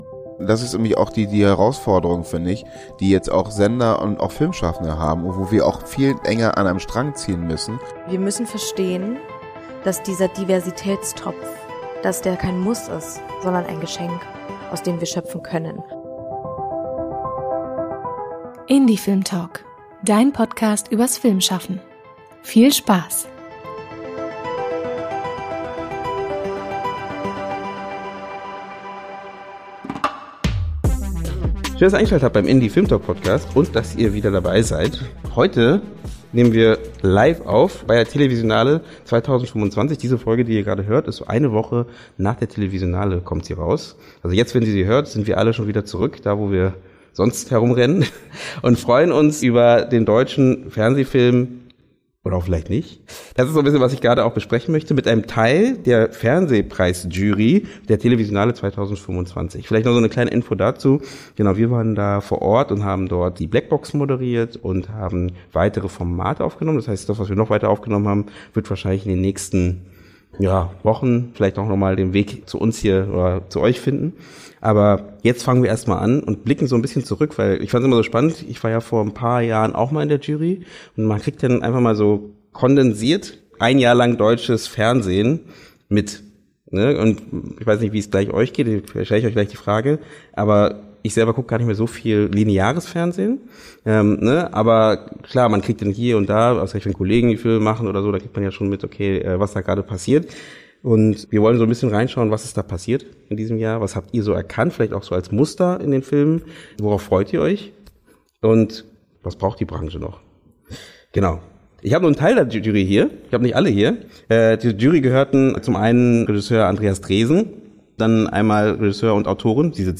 Diese Folge ist live vom Fernsehfilm- und Serienfestival Televisionale 2025 entstanden und beschäftigt sich, wie das Festival, mit der Qualität der deutschsprachigen Fernsehlandschaft.